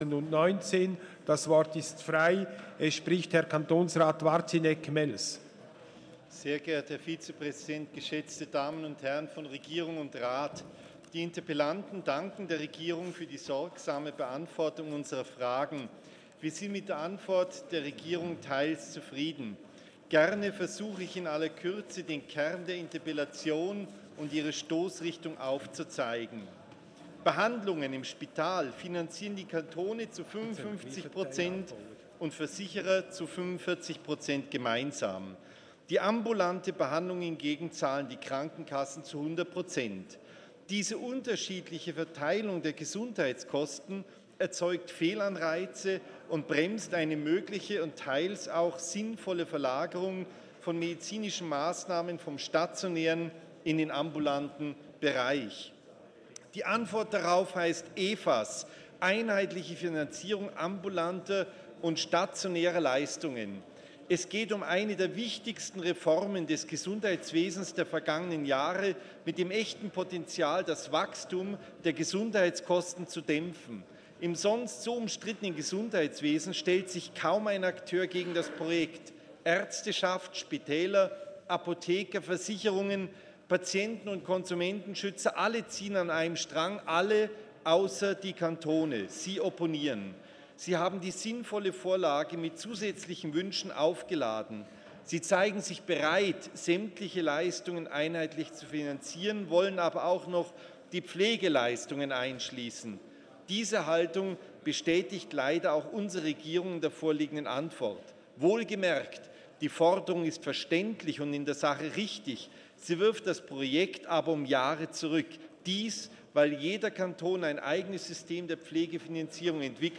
24.4.2019Wortmeldung
Session des Kantonsrates vom 23. und 24. April 2019